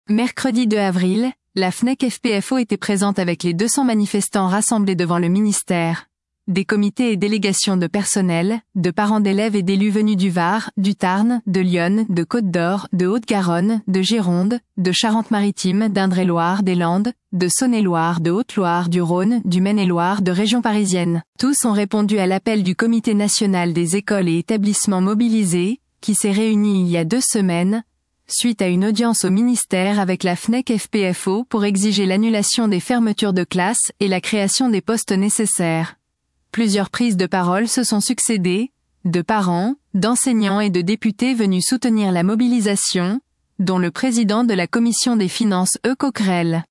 Rassemblement au ministère le 2 avril : La ministre doit répondre ! On ne lâchera rien !
Tous ont répondu à l’appel du comité national des écoles et établissements mobilisés qui s’est réuni il y a deux semaines, suite à une audience au ministère avec la FNEC FP-FO pour exiger l’annulation des fermetures de classes et la création des postes nécessaires. Plusieurs prises de paroles se sont succédé, de parents, d’enseignants et de députés venus soutenir la mobilisation, dont le président de la Commission des Finances E. Coquerel.